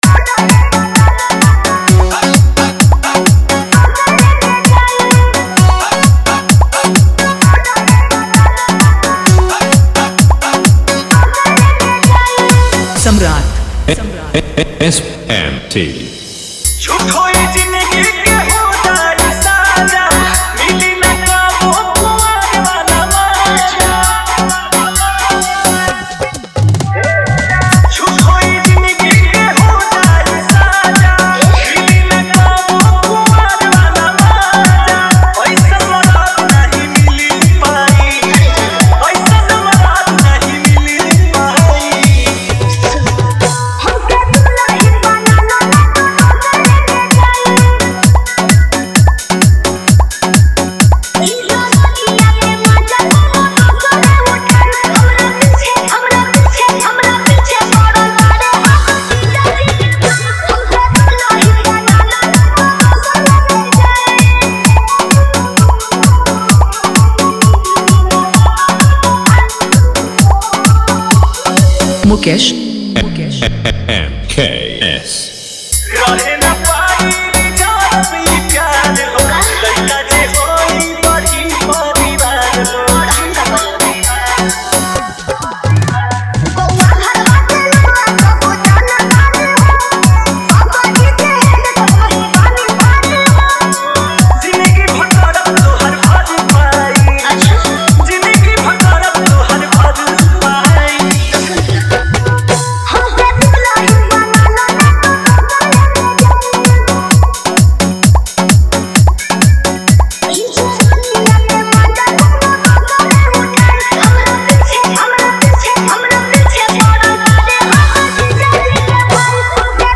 2025 Bhojpuri DJ Remix - Mp3 Songs